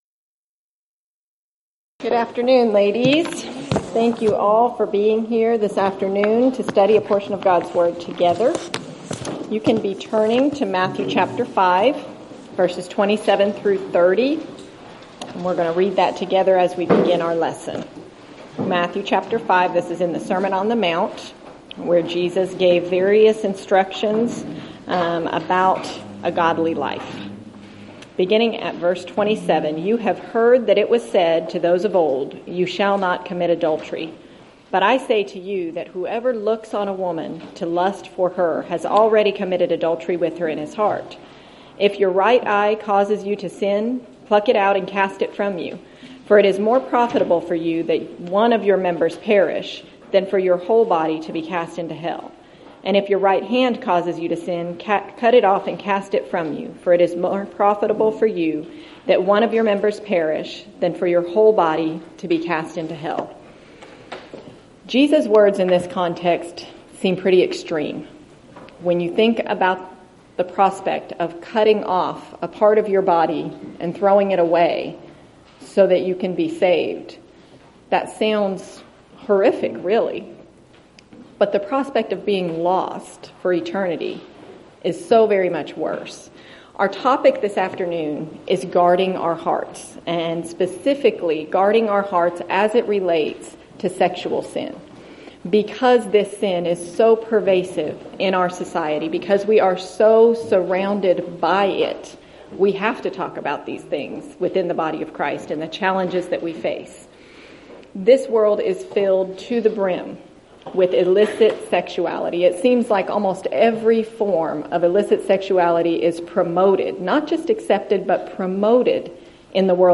Event: 3rd Annual Southwest Spritual Growth Workshop Theme/Title: Arise and Grow as a Family
lecture